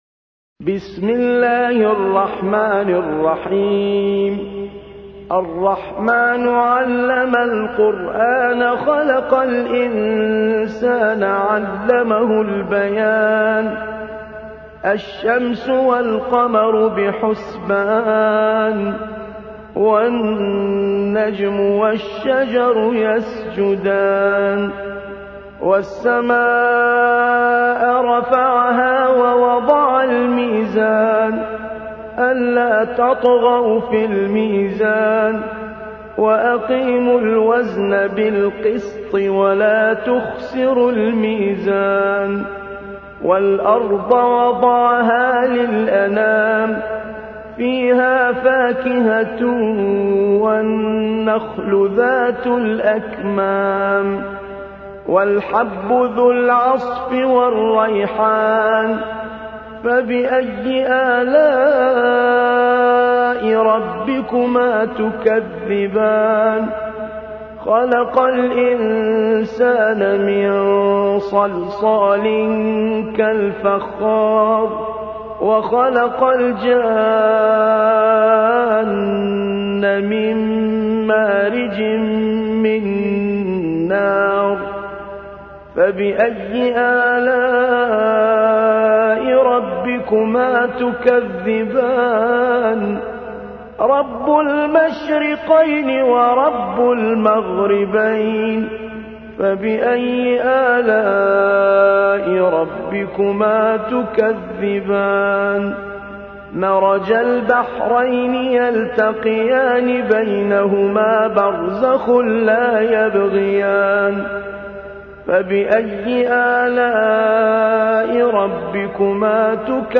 55. سورة الرحمن / القارئ